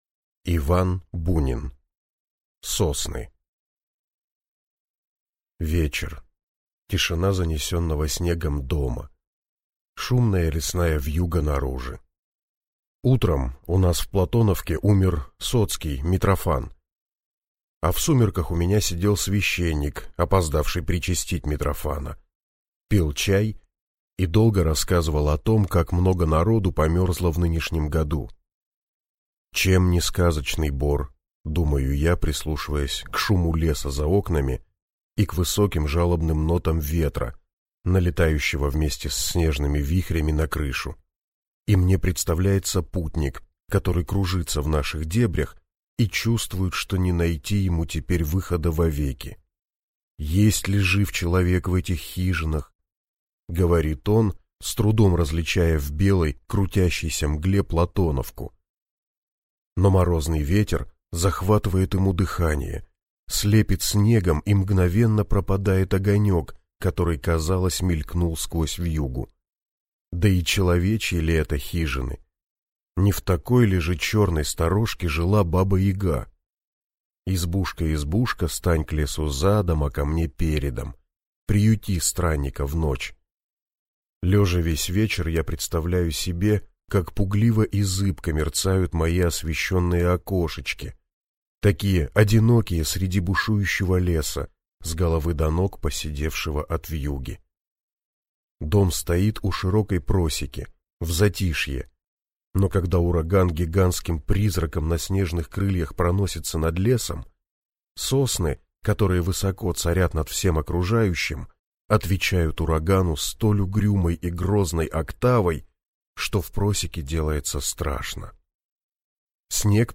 Аудиокнига Сосны | Библиотека аудиокниг